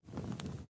smoking_1.ogg